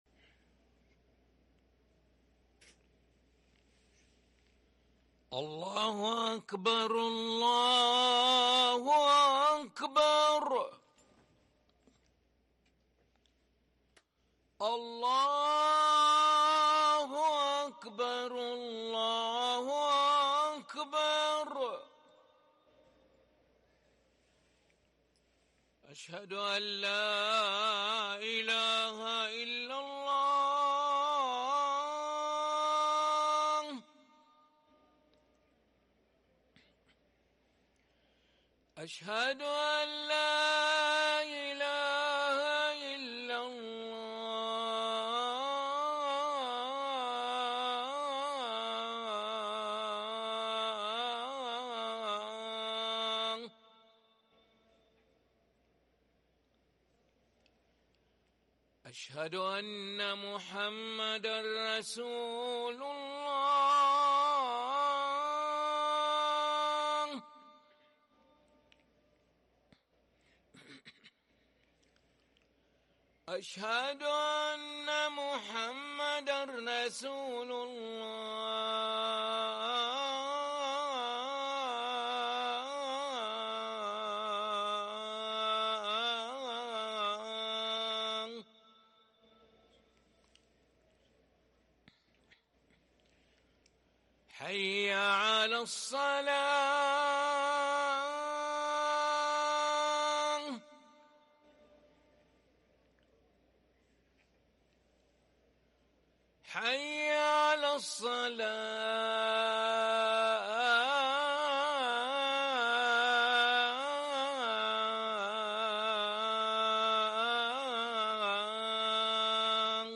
اذان العشاء للمؤذن علي ملا الخميس 6 محرم 1444هـ > ١٤٤٤ 🕋 > ركن الأذان 🕋 > المزيد - تلاوات الحرمين